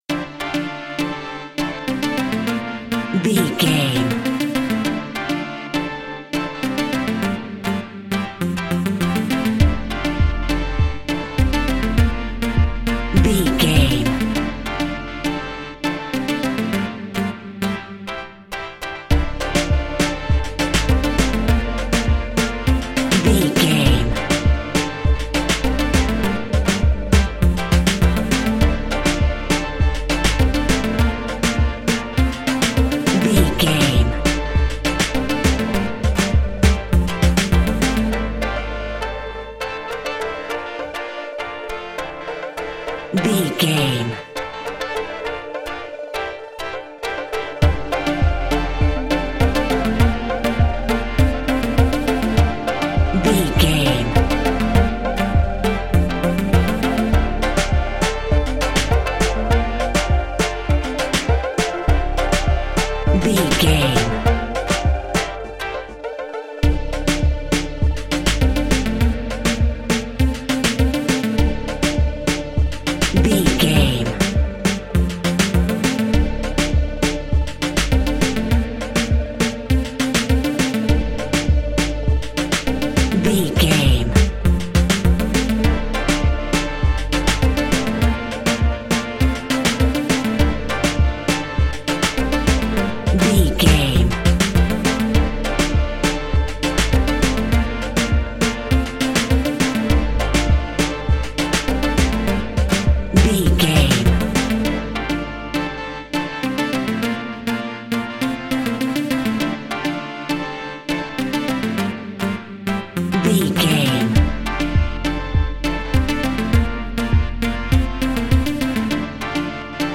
Classic reggae music with that skank bounce reggae feeling.
Aeolian/Minor
D
dub
instrumentals
laid back
chilled
off beat
drums
skank guitar
hammond organ
percussion
horns